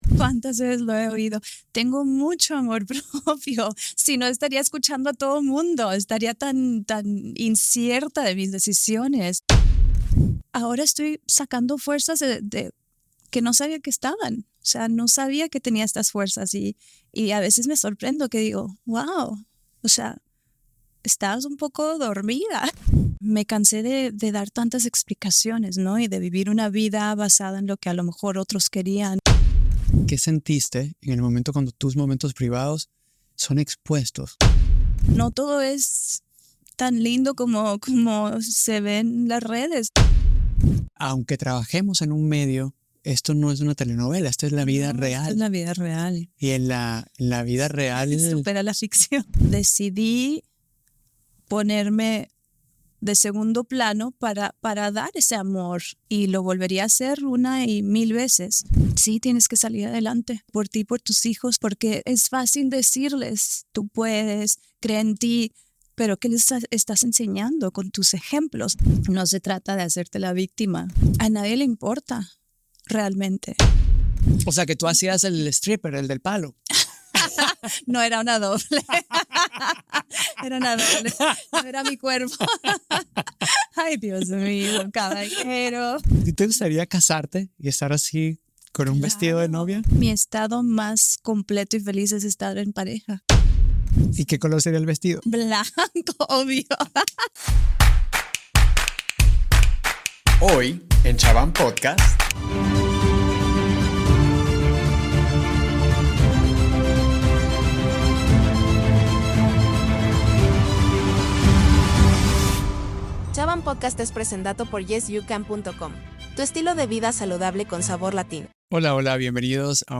Soy Alejandro Chabán, y estoy emocionado de presentarles a una invitada muy especial: la talentosa actriz mexicana Elizabeth Gutiérrez. En esta ocasión, tenemos una conversación profunda y sincera que no se pued...